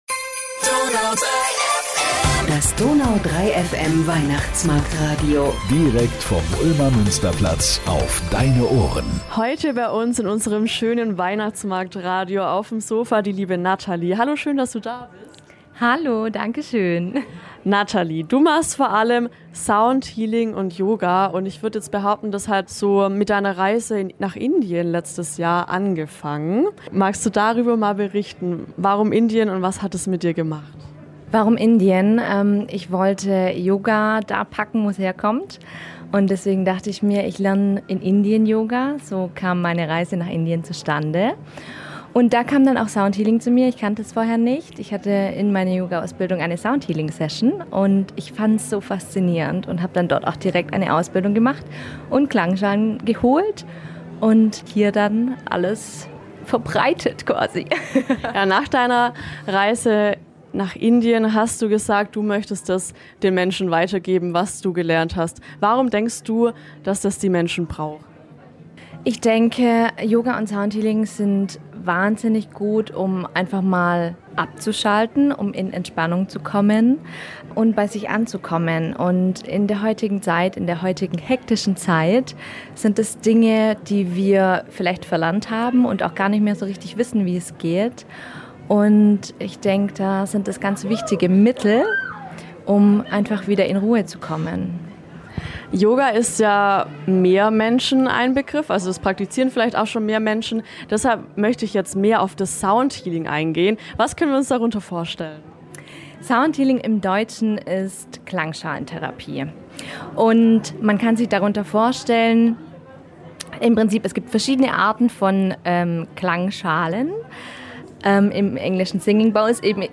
Im Interview lernen wir ganz viel über Sound Healing und wie es uns im Alltag hilft.